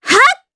Kirze-Vox_Attack4_jp.wav